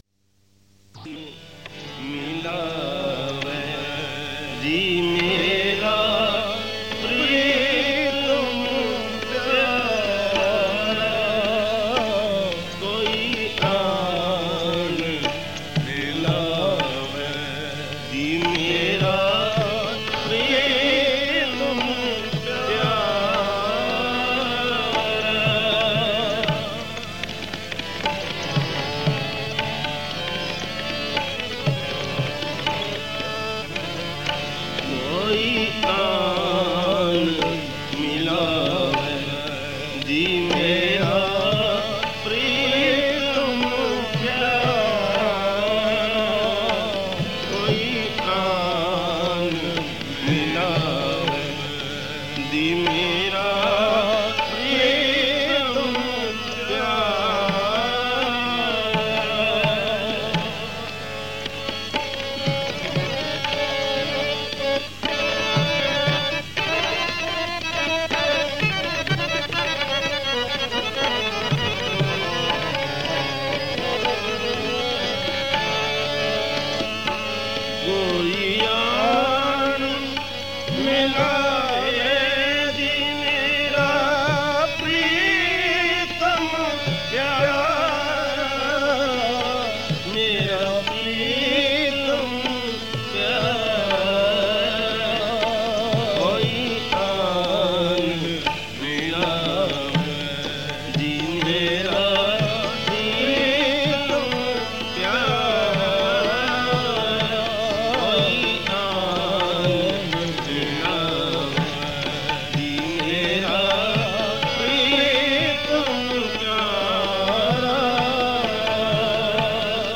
The recordings below are from his visit to the UK in 1981.